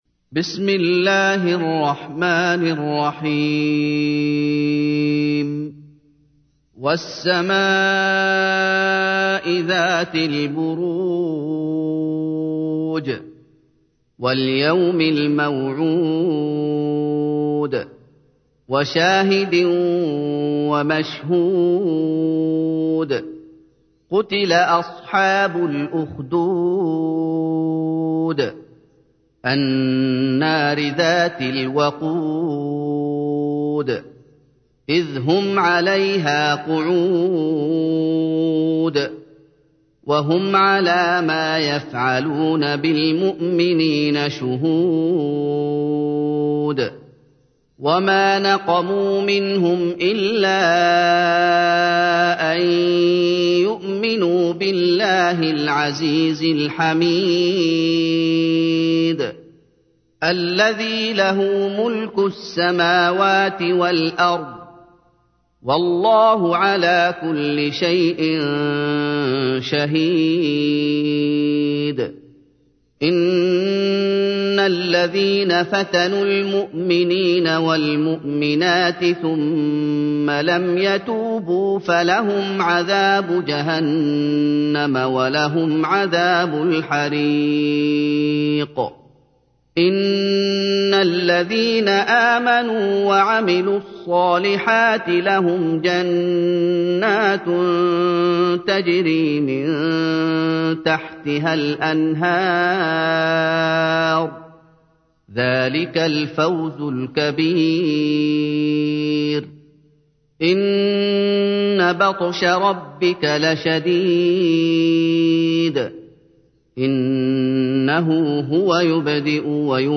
تحميل : 85. سورة البروج / القارئ محمد أيوب / القرآن الكريم / موقع يا حسين